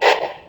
step.ogg